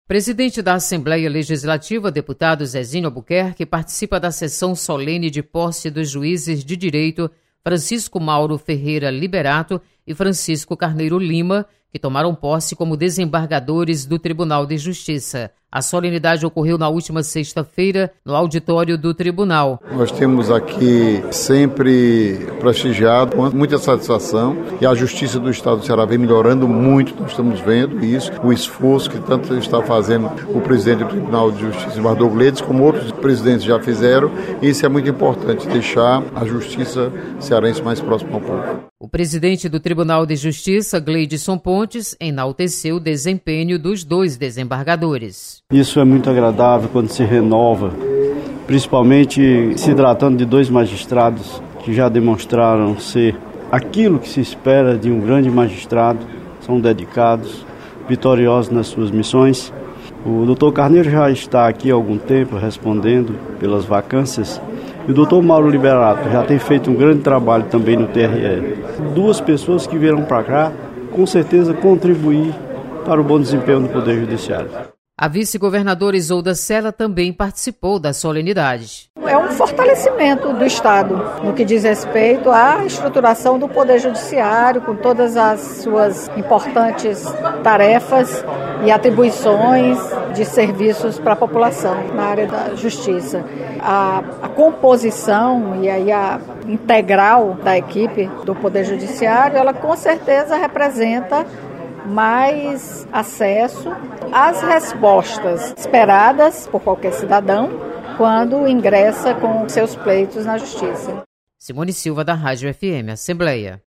Deputado Zezinho Albuquerque participa da posse de Juízes de Direito.